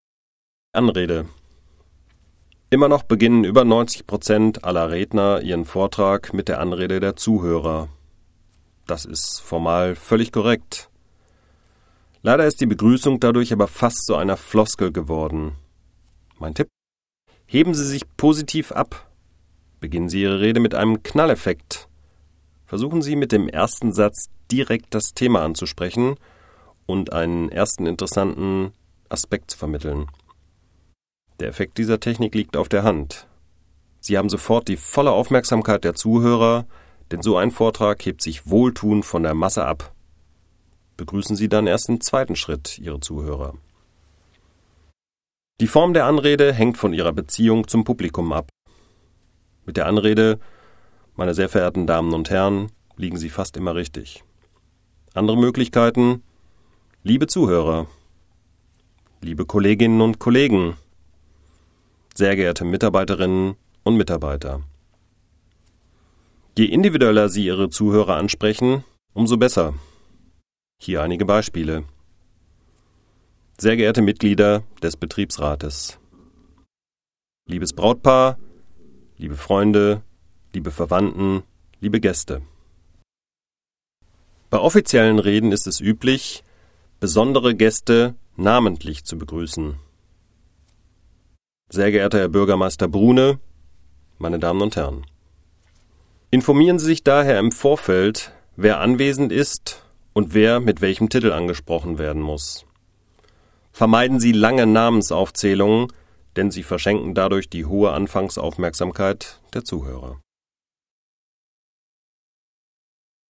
Downloads zu dem Rhetorik Hörbuch “Rhetorik von A - Z”